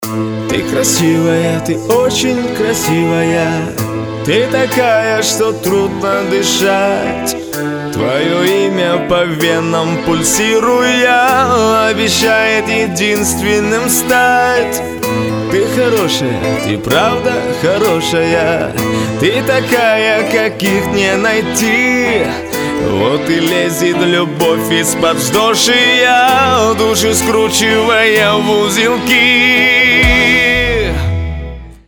• Качество: 320, Stereo
поп
красивый мужской голос
спокойные